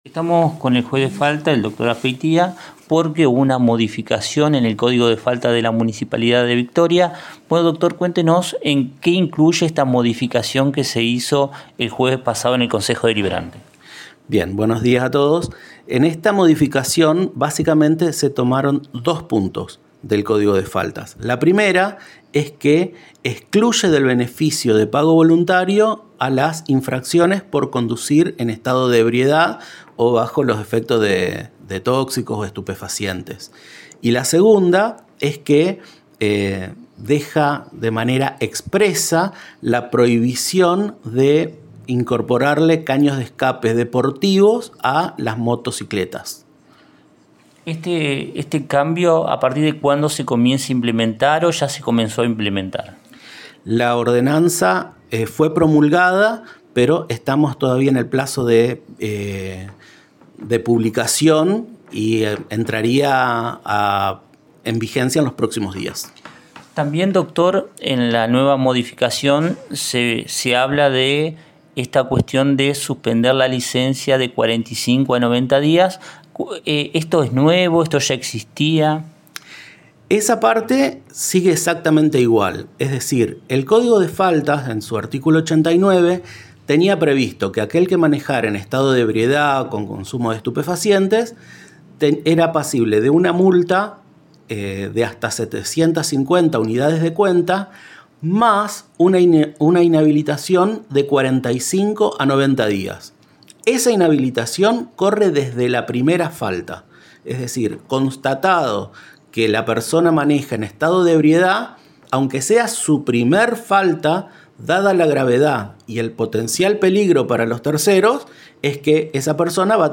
Entrevistas